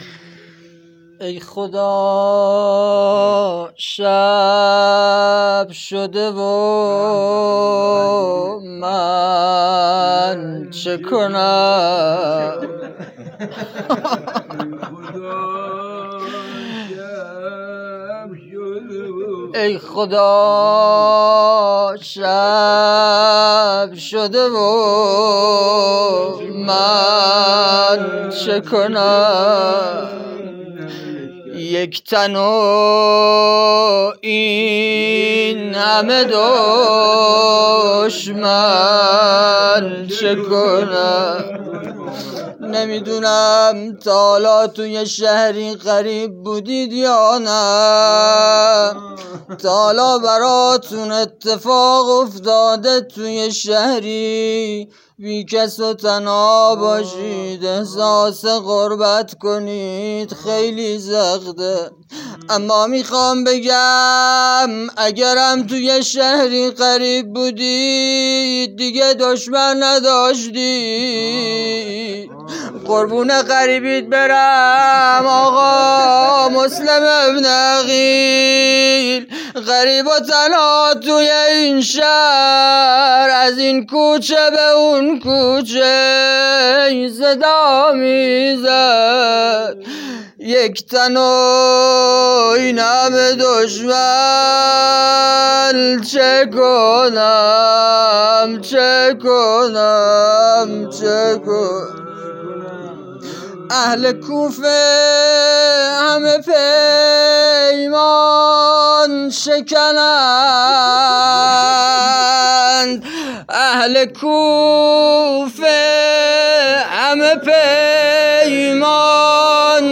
روضه
محرم 1403